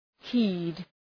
Προφορά
heed.mp3